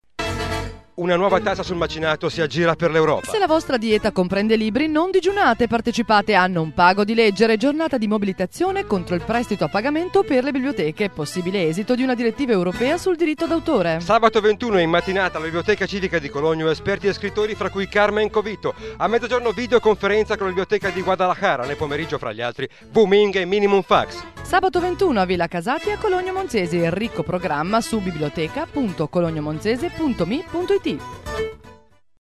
Spot su Radio Popolare (.mp3) iniziativa Cologno del 21 febbraio